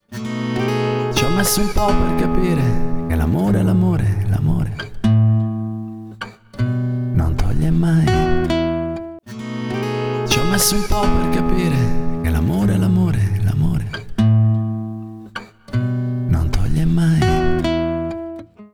La modalità Brown ci sembra indicata per voce e chitarre elettriche, quella Black tira fuori i particolari nelle acustiche che, sulle acute, diventano dettagliate senza essere fastidiose.
Nelle clip di esempio si parte con il suono non equalizzato, l’effetto viene inserito successivamente per lasciar apprezzare le differenze.
MaleVox
MaleVox.wav